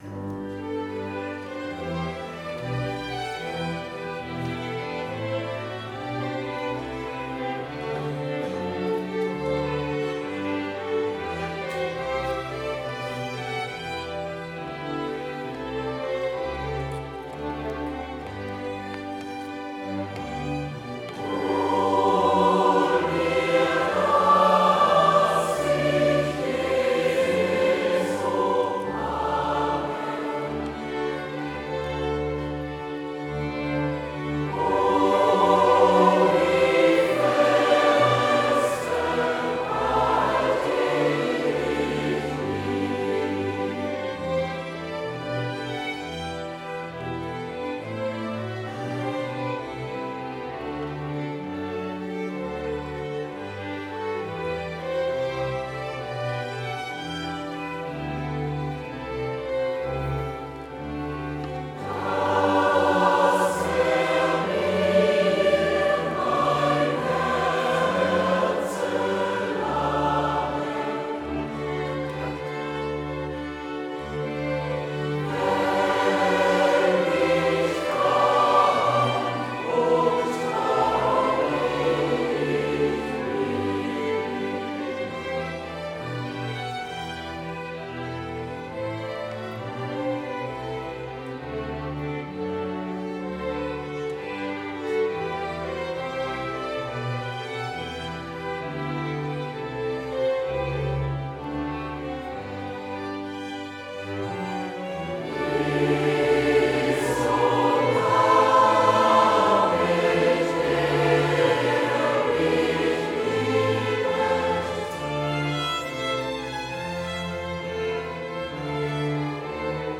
17.02.2019 (Christus-Kirche)
Perlen geistlicher Chormusik
Choral aus der Kantate "Herz und Mund und Tat und Leben", BWV 147
Kammerorchester